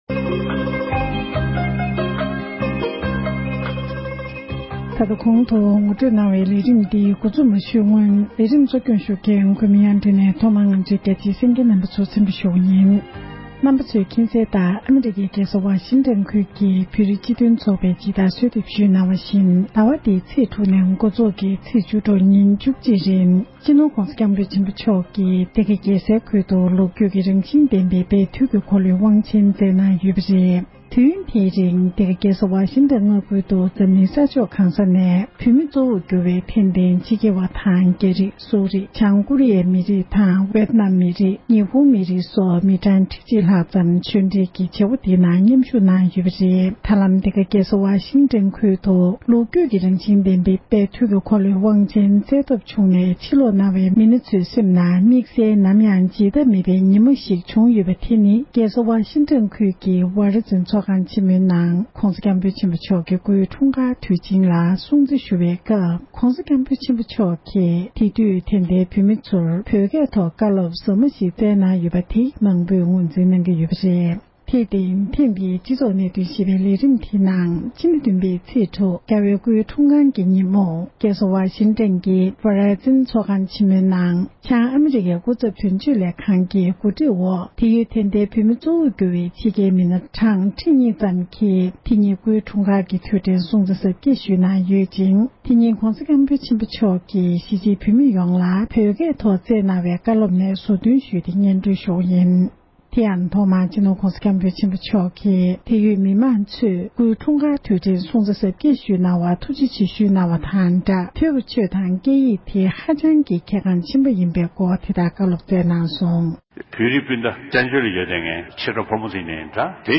༸གོང་ས་མཆོག་ནས་ཨ་རིའི་རྒྱལ་ས་ལྦ་ཤིང་ཊོན་དུ་སྐུའི་འཁྲུངས་སྐར་གྱི་ཉིན་མོར་བོད་སྐད་ཐོག་བསྩལ་བའི་བཀའ་སློབ།